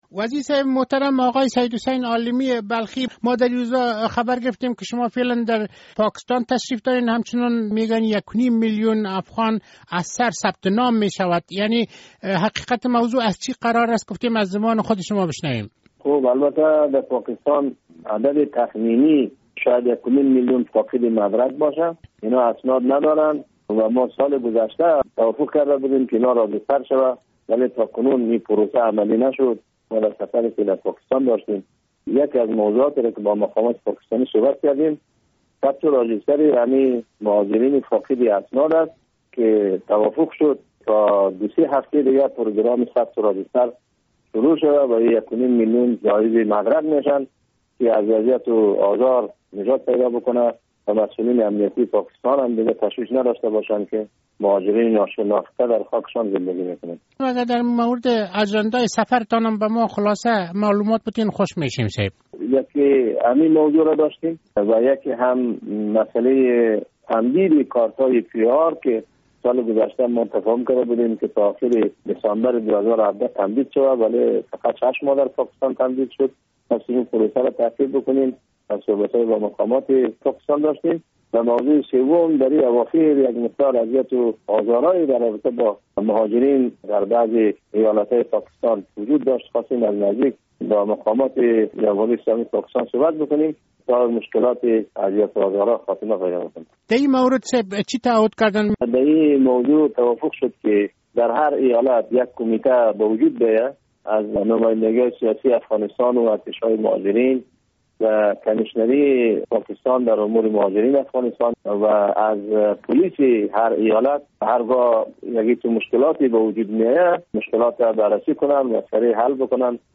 مصاحبه - صدا
مصاحبه با آقای سید حسین عالمی بلخی وزیر امور مهاجرین و عودت کنندگان در مورد سفر شان به پاکستان...